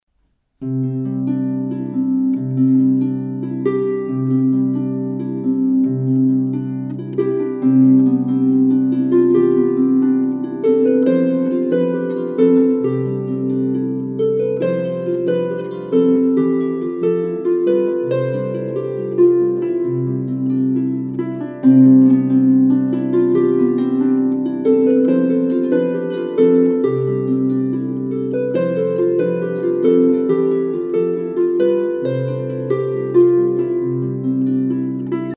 traditional carols